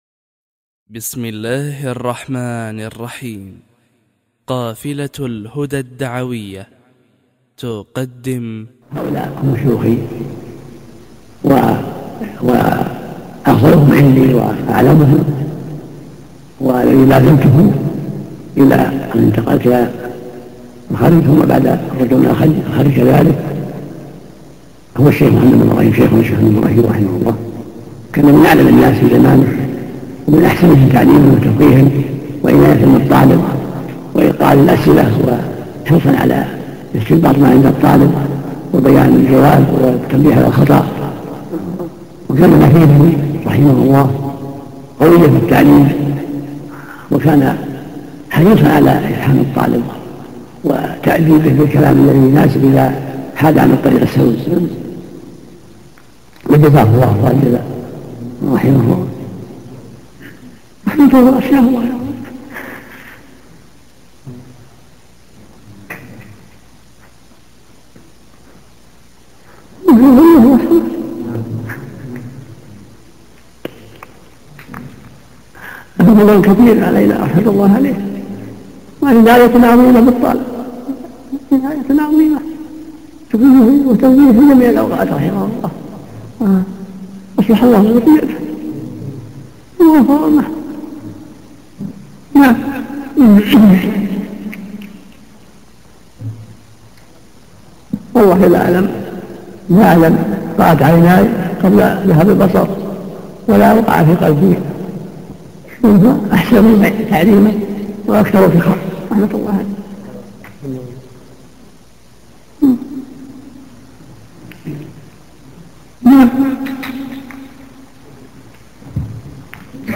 When he would cry he would do so with a very faint sound, the effects would be seen on his face, or tears could be seen flowing from his eyes, he didn’t like that the voice be raised when crying.
[Here he was crying when talking about his Shaikh Muhammad Ibn Ibrahim, the Mufti of Saudi Arabia before him]
ibn-baaz-crying.mp3